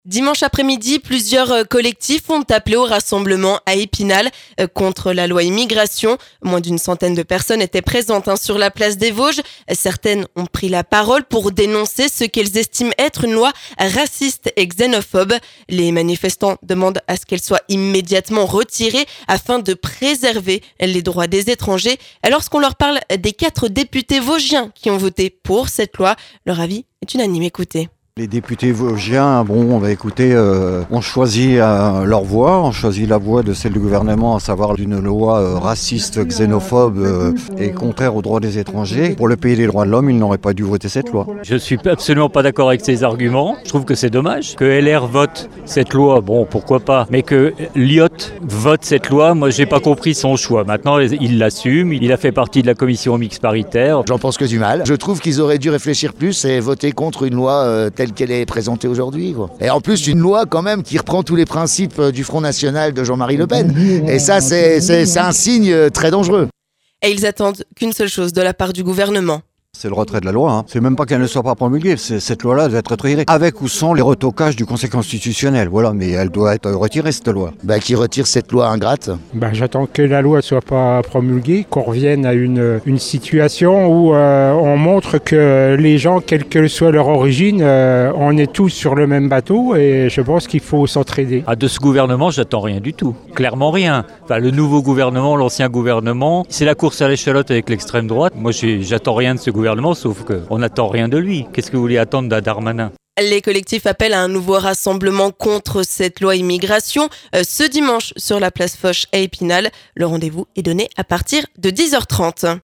Sur place, nous avons tendu notre micro aux manifestants.